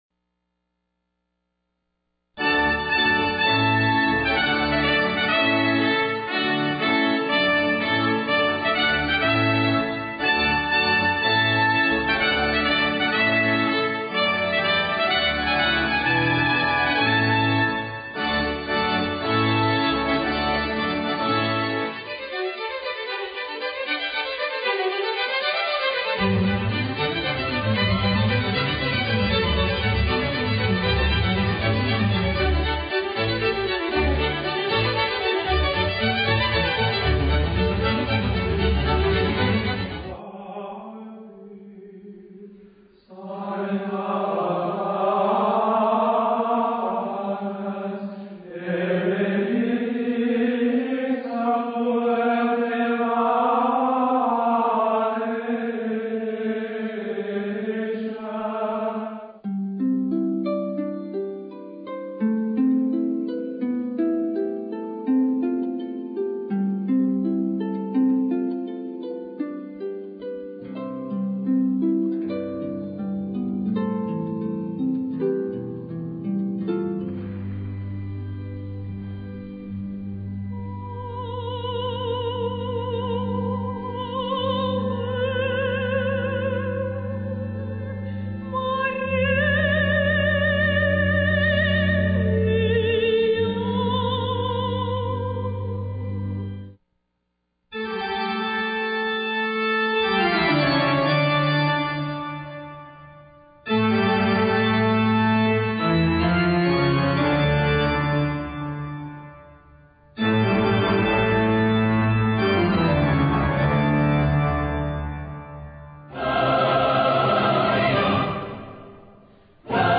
Instrumental/vocal.